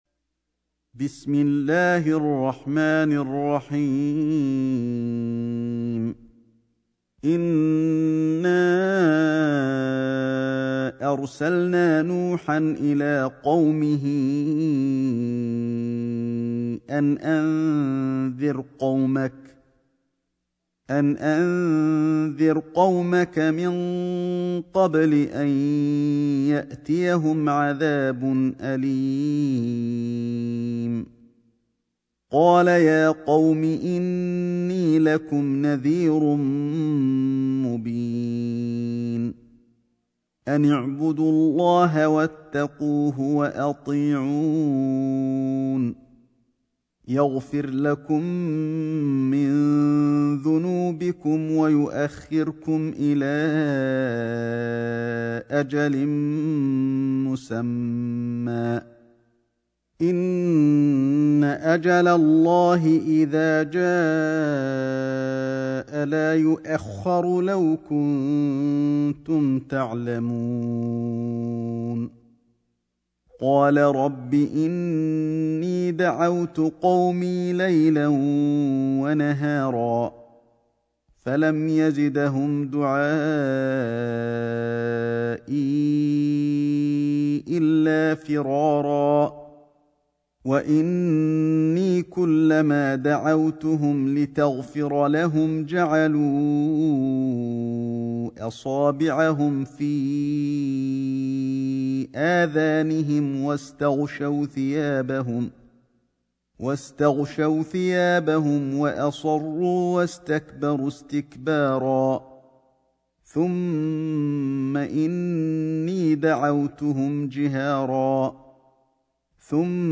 سورة نوح > مصحف الشيخ علي الحذيفي ( رواية شعبة عن عاصم ) > المصحف - تلاوات الحرمين